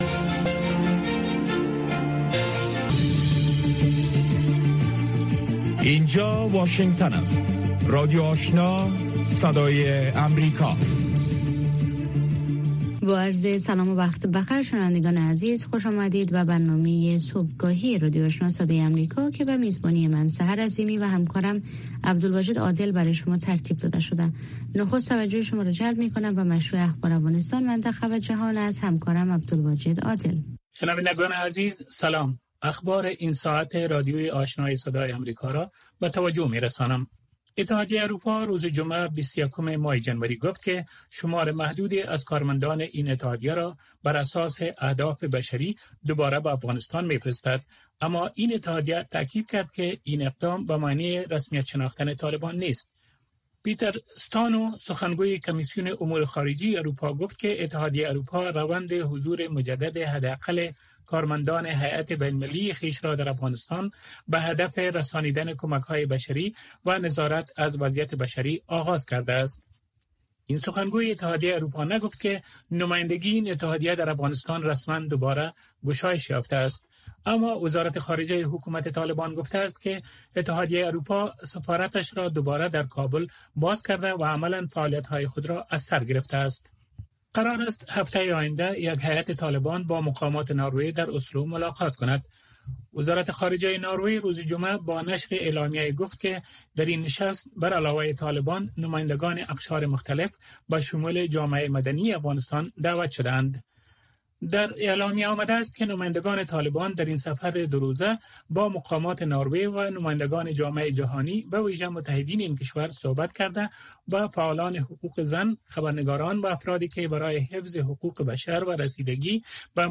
در برنامۀ صبحگاهی خبرهای تازه از افغانستان و جهان و گزارش‌های تحلیلی و مصاحبه ها در پیوند با رویدادهای داغ افغانستان و جهان به شما پیشکش می شود.